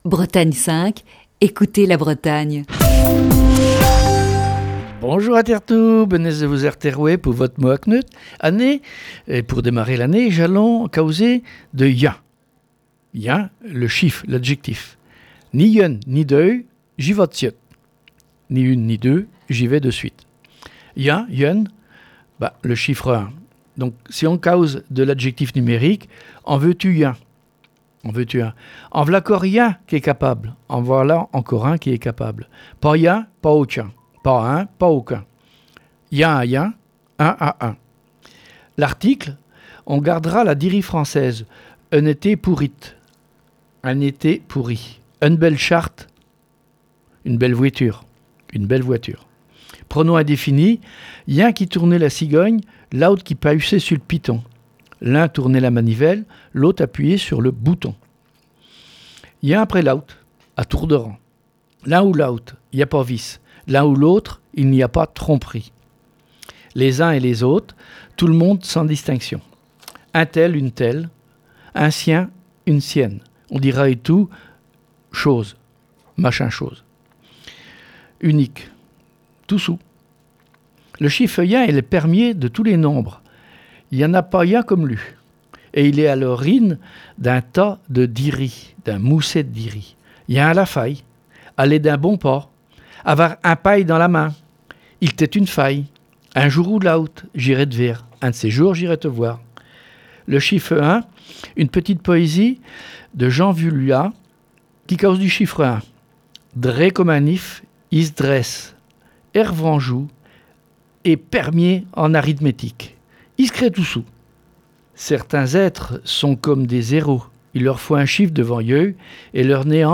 Chronique du 6 septembre 2021.